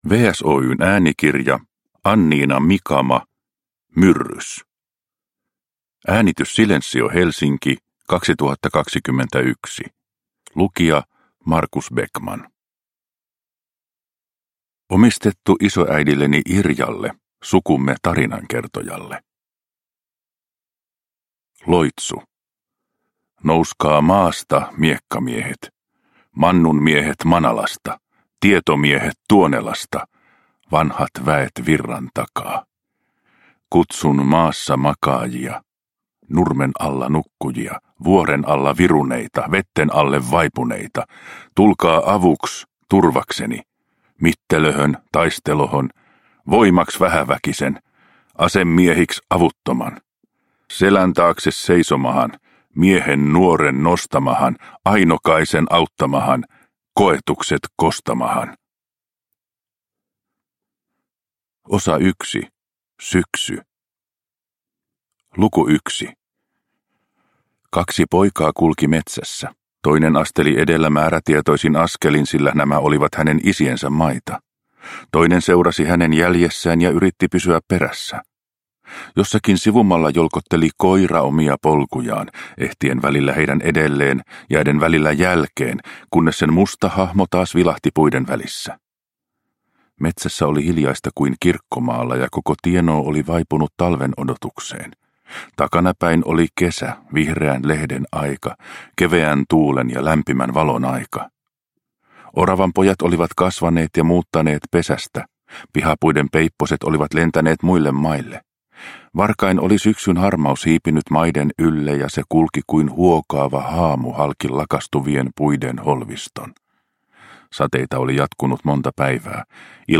Myrrys – Ljudbok – Laddas ner